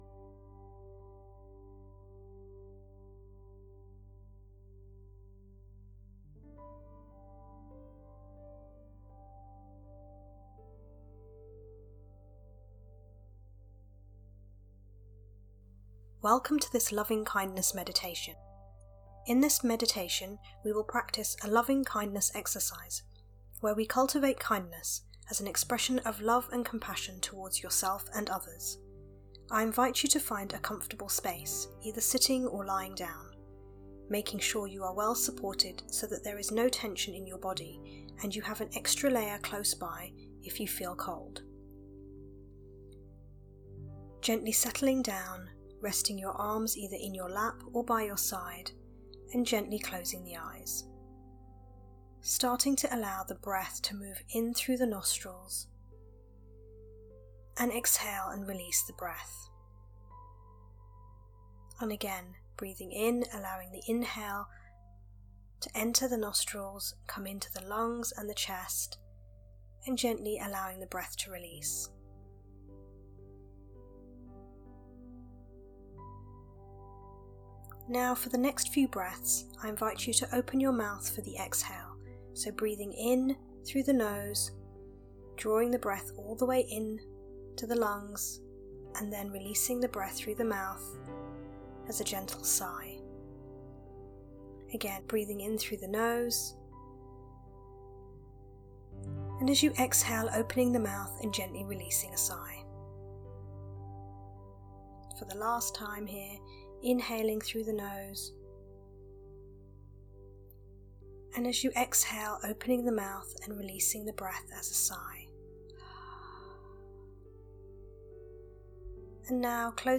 One great way to help settle your mind and body, all the while cultivating inner and outer peace over the festive season is through using a guided meditation .